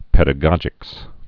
(pĕdə-gŏjĭks, -gōjĭks)